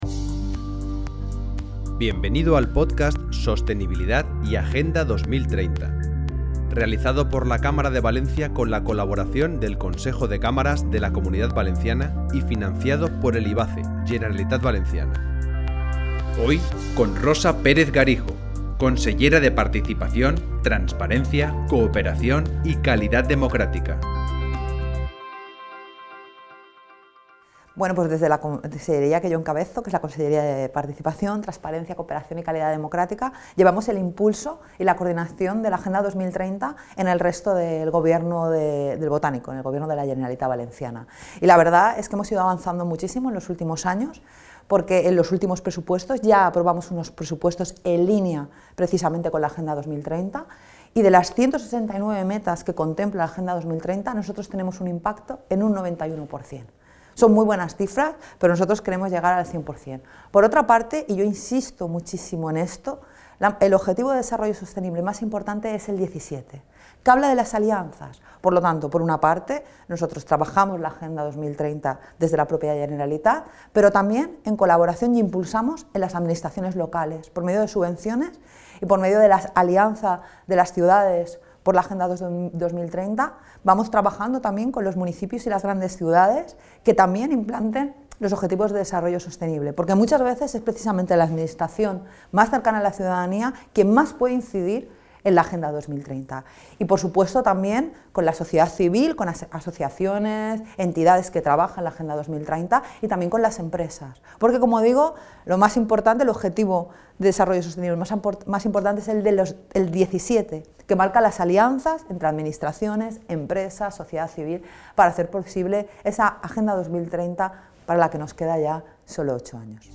Con el podcast “La revolución 4.0” pretendemos realizar una foto desde dentro de las propias compañías, entrevistando a los CEO, directivos y/o responsables de la transformación digital de un gran número de empresas de Valencia, algunas de la Comunidad Valencia y también del territorio nacional.